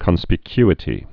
(kŏnspĭ-kyĭ-tē)